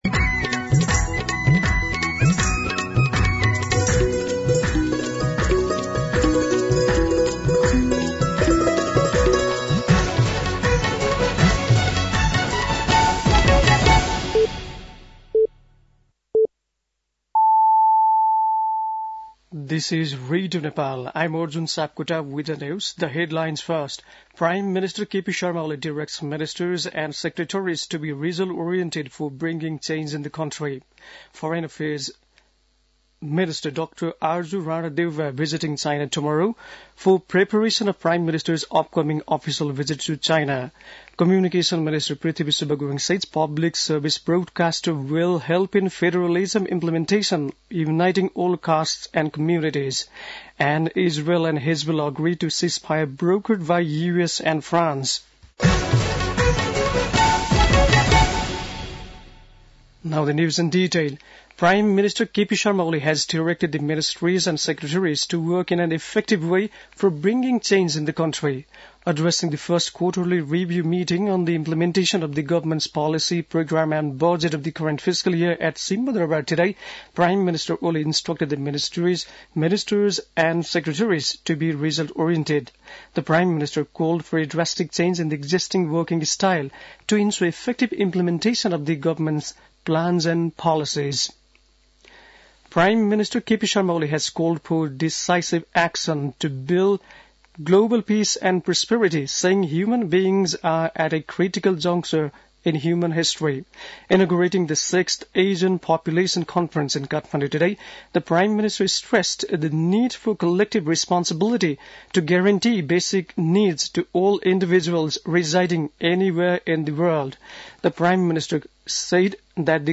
बेलुकी ८ बजेको अङ्ग्रेजी समाचार : १३ मंसिर , २०८१
8-PM-English-NEWS-8-12.mp3